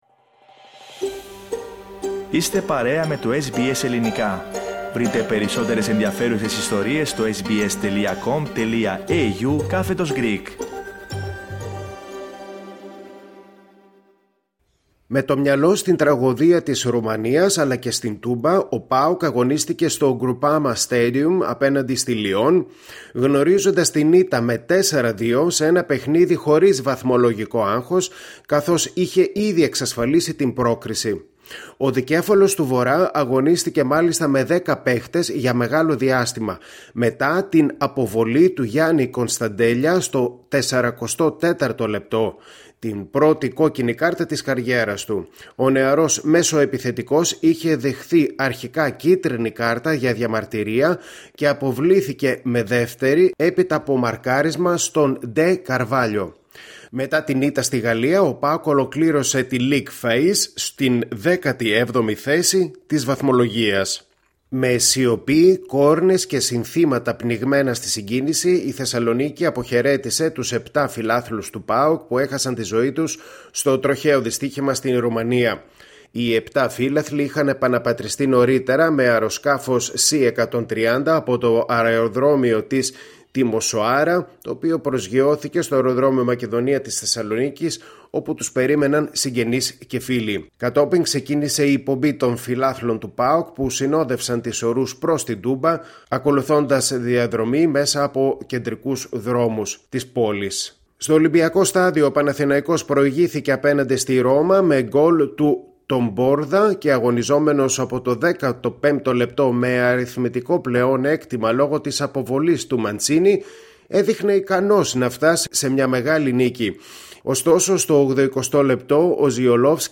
Ακούστε το αθλητικό δελτίο της ημέρας